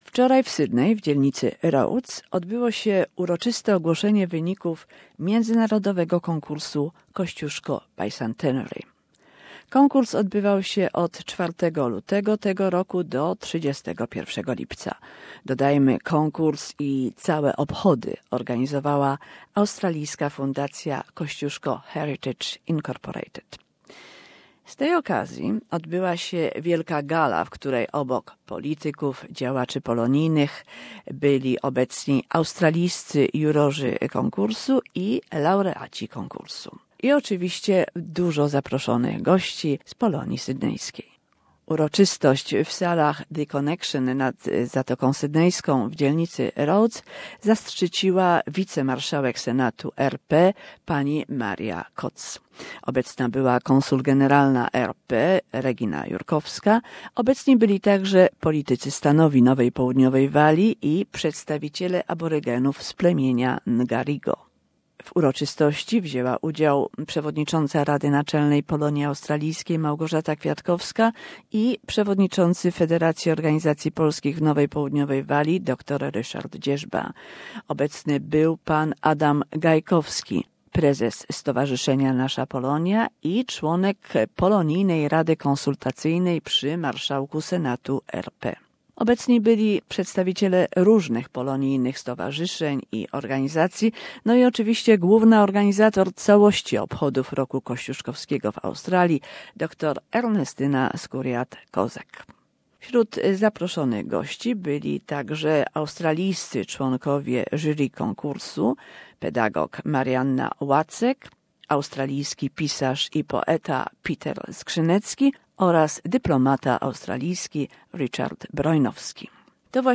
Relacja z uroczystego wręczania nagród Międzynarodowego Konkursu Kosciuszko Bicentenary oraz rozmowa z Maria Koc, wice-marszalkiem Senatu RP. Większość nagród została ufundowana dzięki dotacji Senatu RP oraz Stowarzyszenia „Wspólnota Polska”.